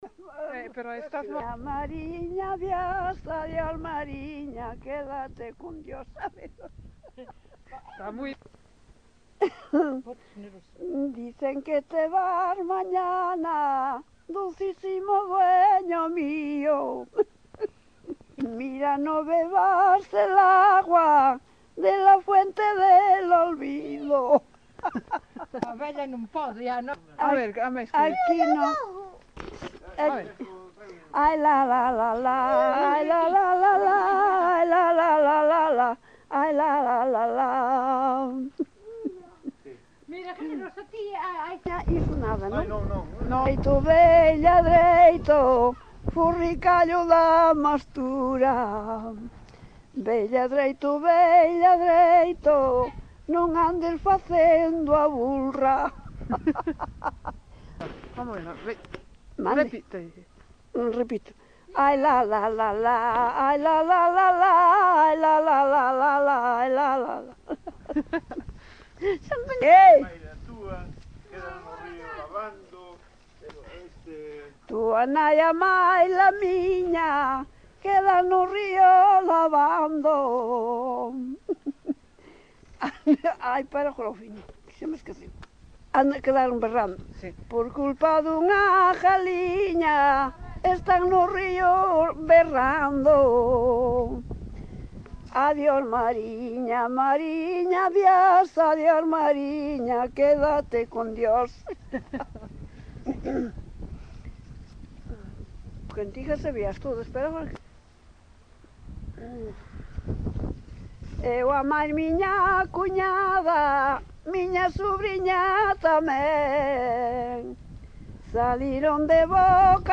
Coplas -
Notas da recolla